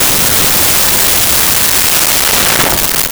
High Powered Rifle 2
High Powered Rifle_2.wav